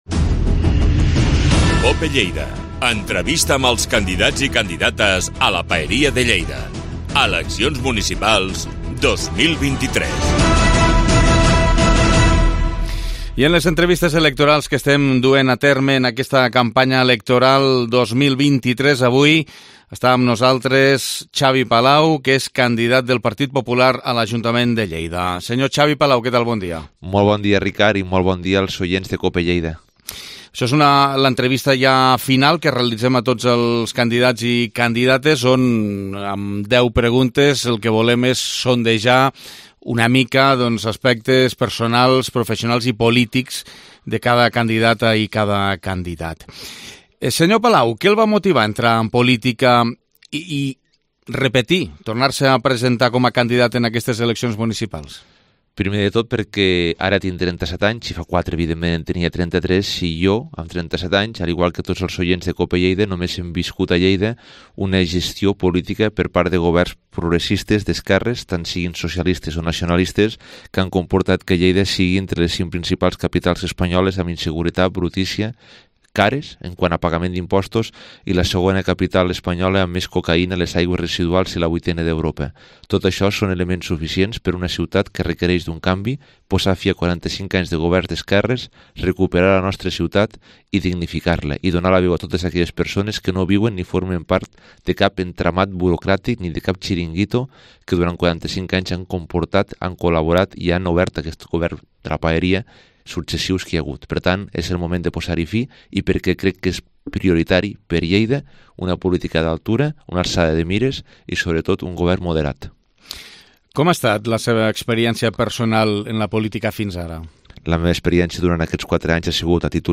Entrevista Campanya Electoral 2023